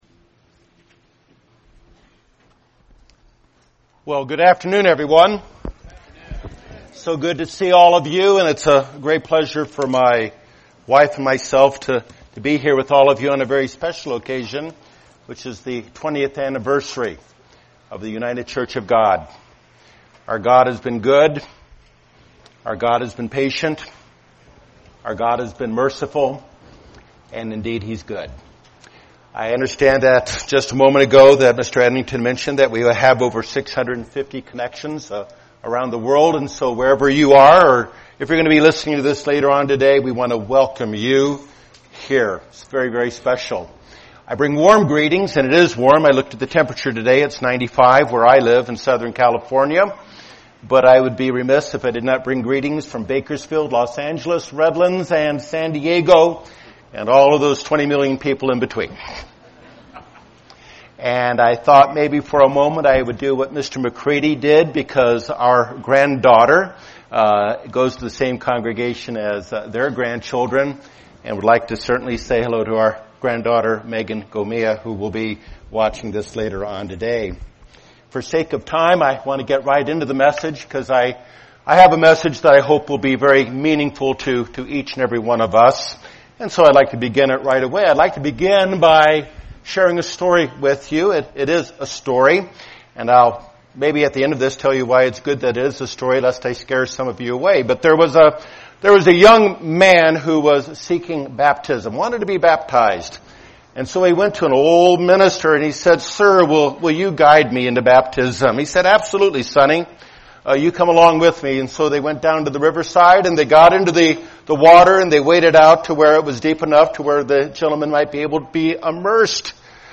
Sabbath Services Transcript This transcript was generated by AI and may contain errors.